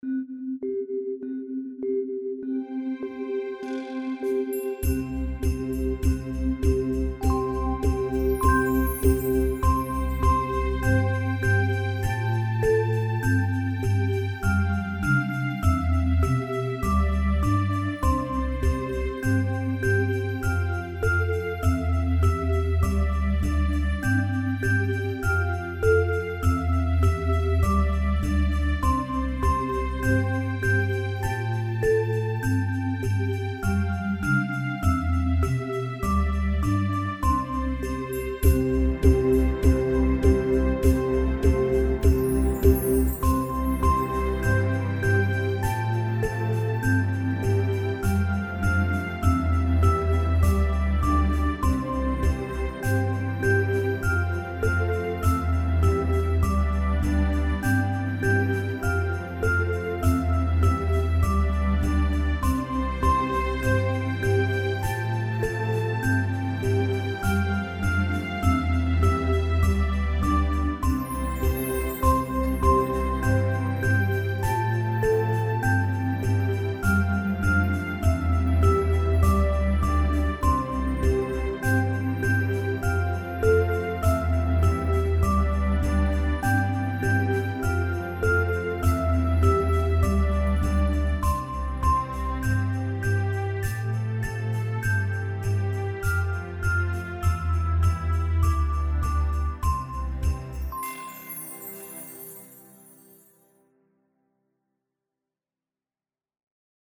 Karaoke Songs for Kids
instrumental
4/4 – 100 bpm